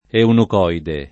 [ eunuk 0 ide ]